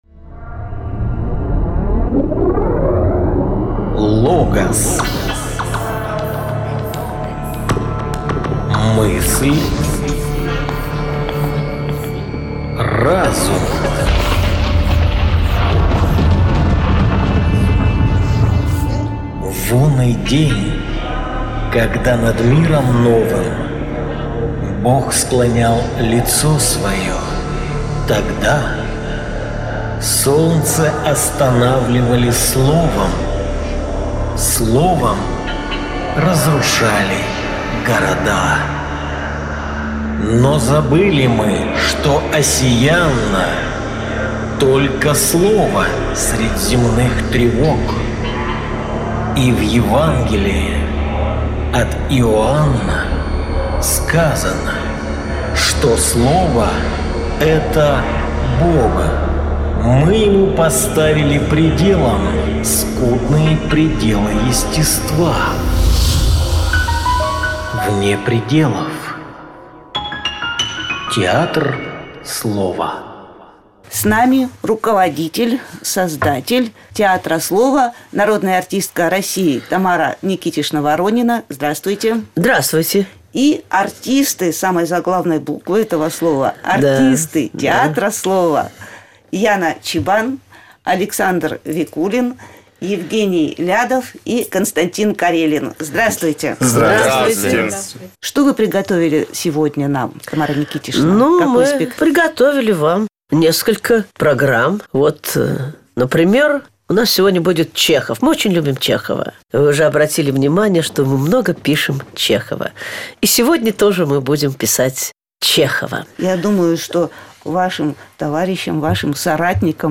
В нашей программе сегодня: Рассказы А.П.Чехова: “Шуточка”,”Женщина без предрасудков” и замечательно разыгранный артистами “Театра Слова” радиоспектакль “Лошадиная фамилия”
Программа выходит в эфире “Радио Урала” с февраля 2009 года.